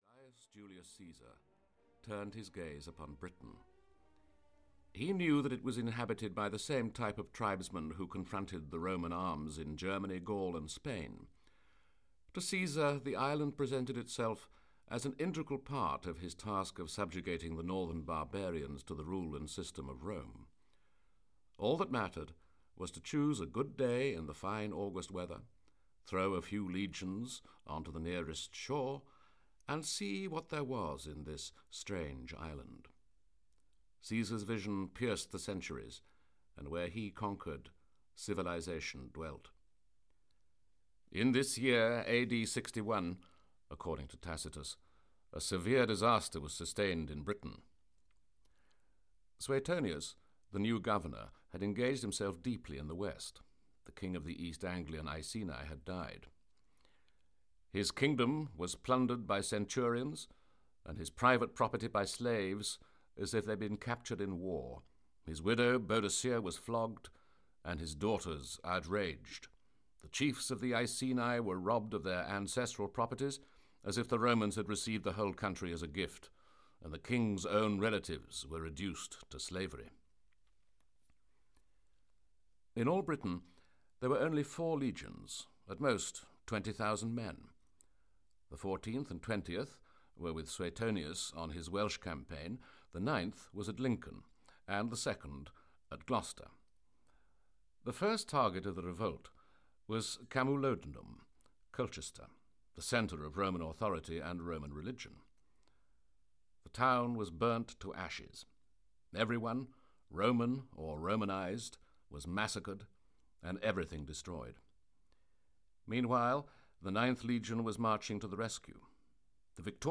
Audio kniha